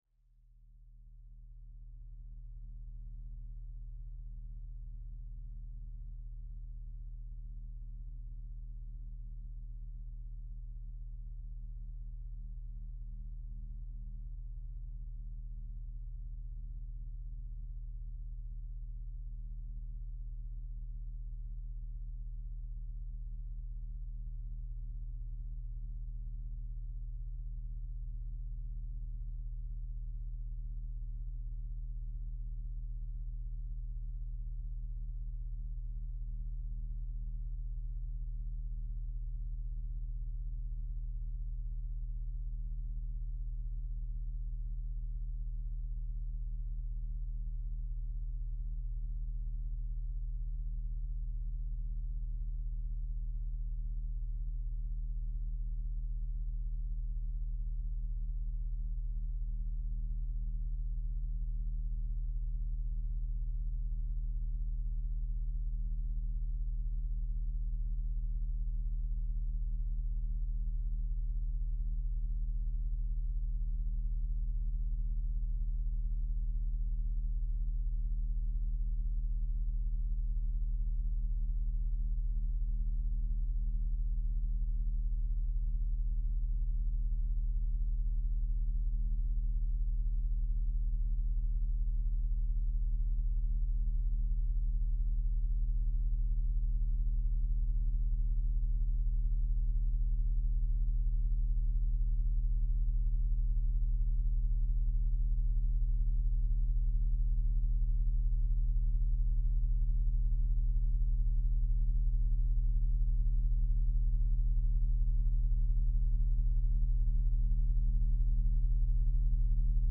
for moving the air in the Rothko Room of Tate Modern as part of >>Materials Library Presents... on Monday the 6th of November 2006
N.B: this track is designed to be listened to through large speakers, although small headphones will produce an effect, the quality will be diminished.